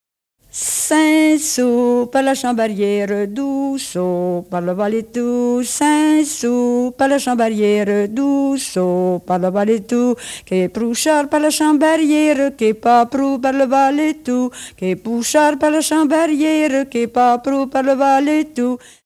Cinc sòus per la chambriera (polka piquée
chant n° 21
Enregistrée en 1976 à Mézières-sur-Issoire (Haute-Vienne)